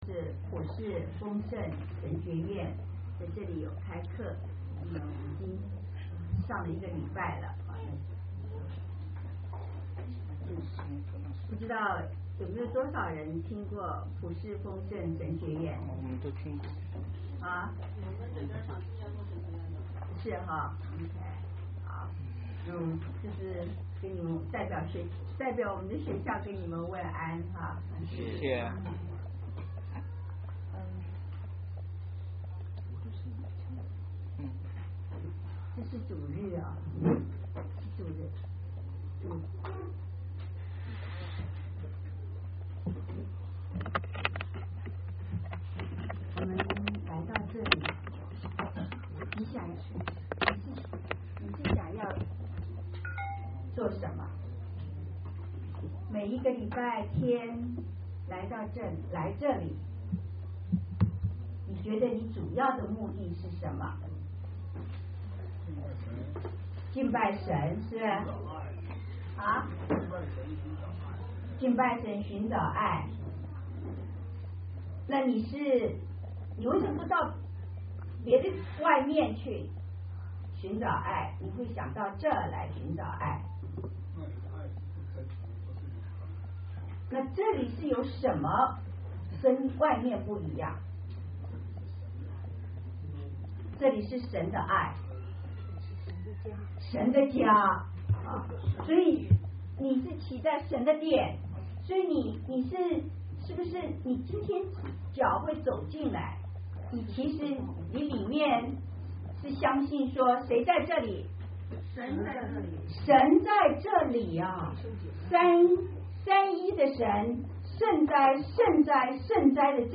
2011年3月13日国语团契讲道 - Powered by Discuz!